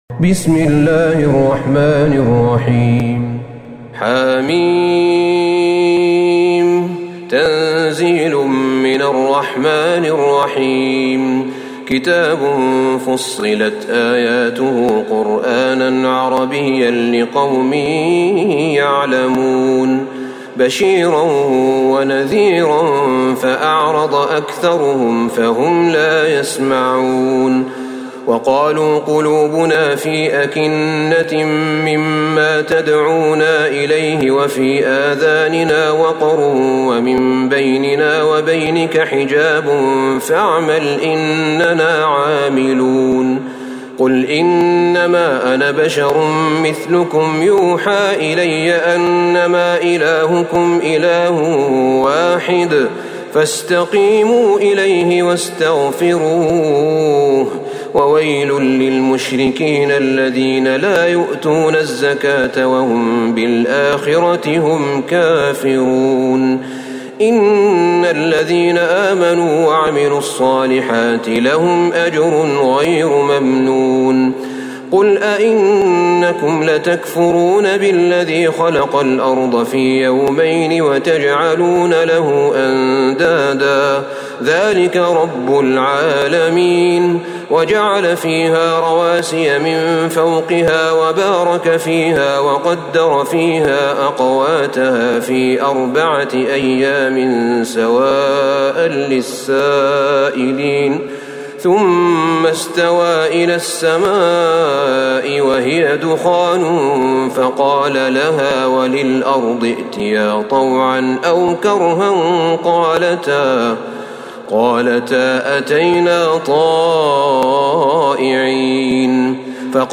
سورة فصلت Surat Fussilat > مصحف الشيخ أحمد بن طالب بن حميد من الحرم النبوي > المصحف - تلاوات الحرمين